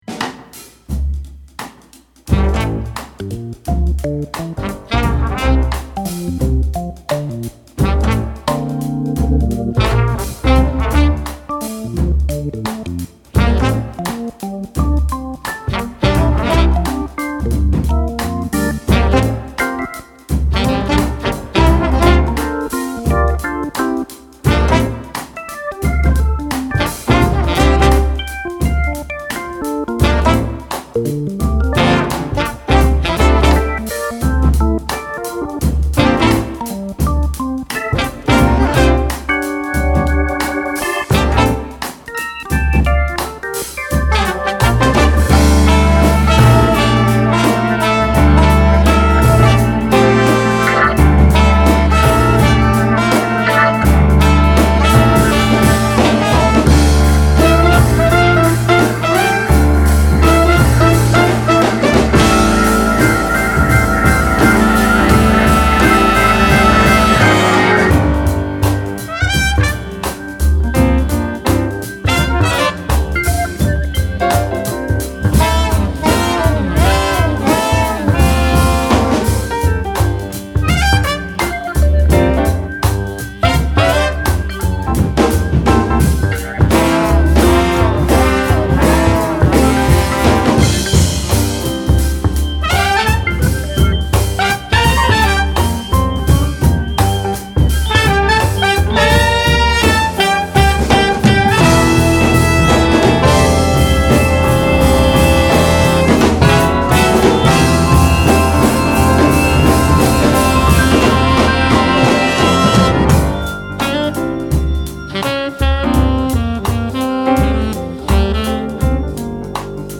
Jazz, funk, progressive rock, fusion, pop.